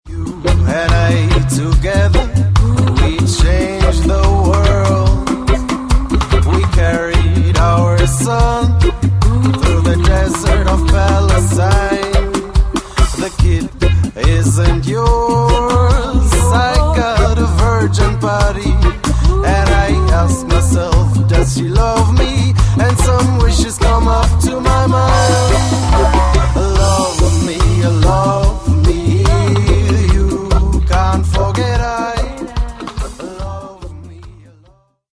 hinzu kommt ein Mix aus zwei Vocals.